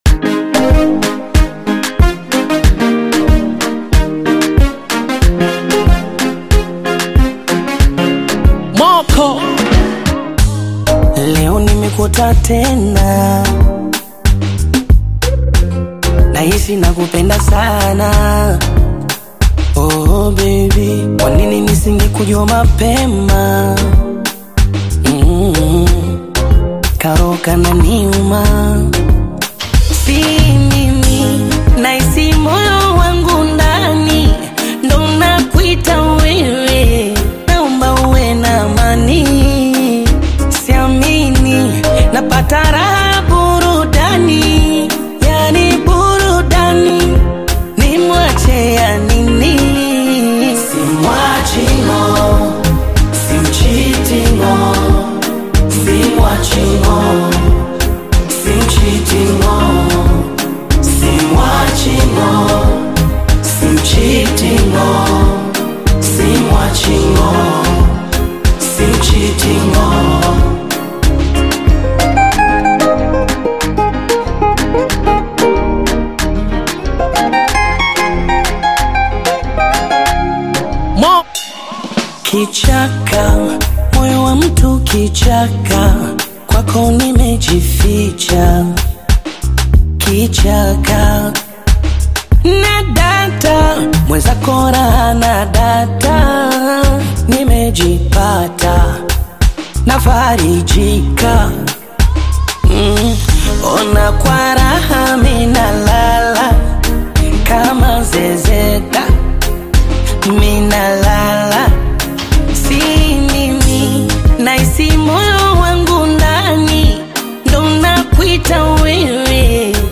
romantic single
soulful Bongo Fleva